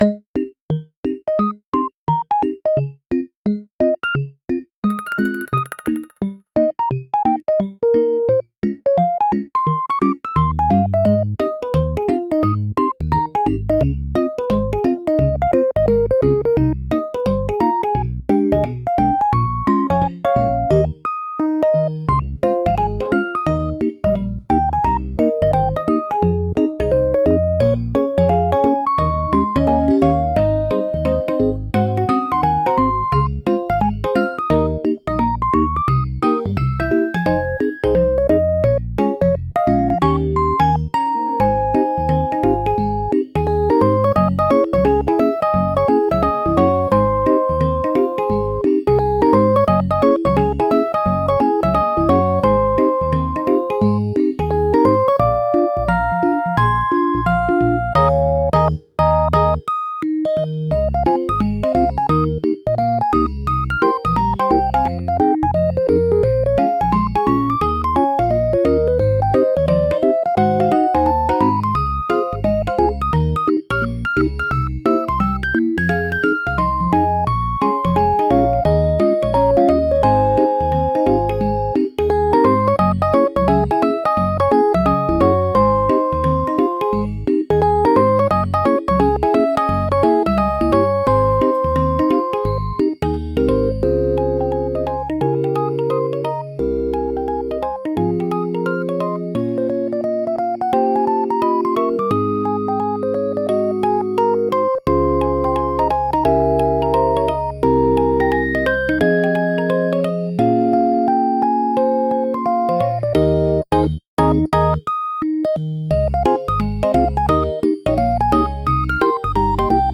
ピコピコ軽やか／ゲーム風BGM